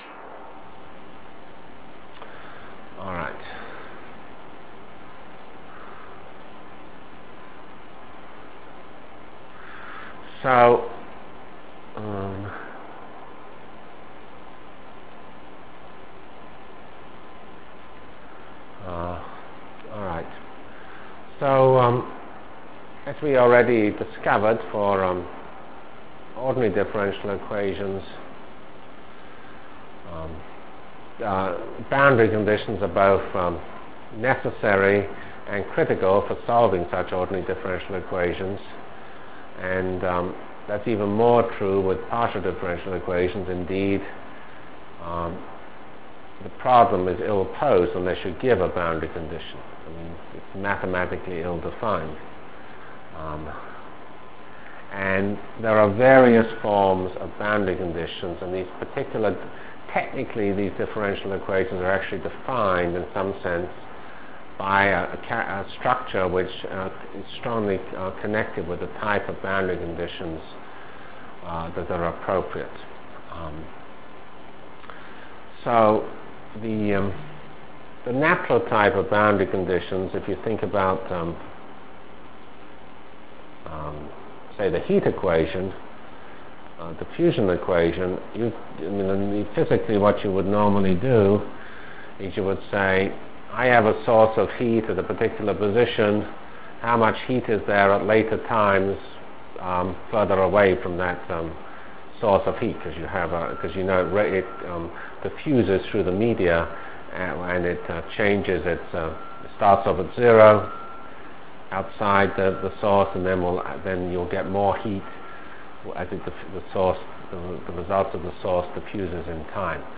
Delivered Lectures